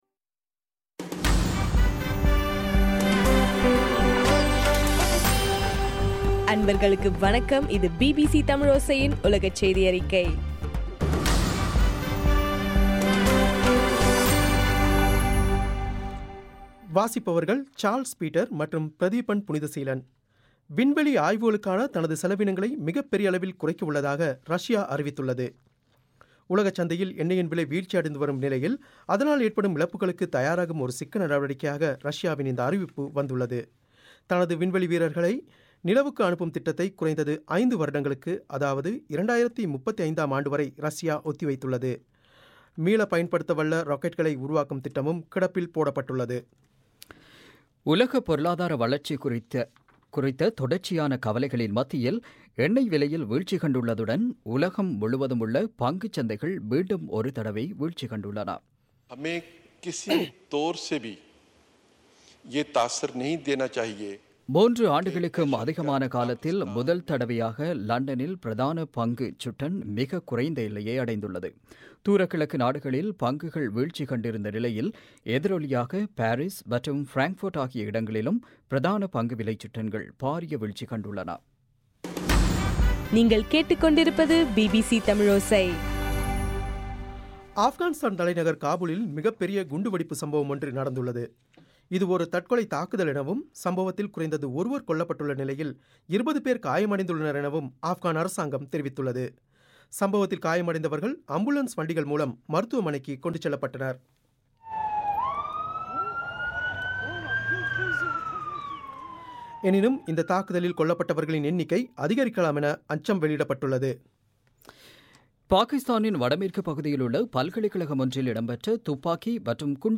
ஜனவரி 20 பிபிசியின் உலகச் செய்திகள்